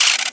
Sfx_tool_spypenguin_invalid_deploy_cam_01.ogg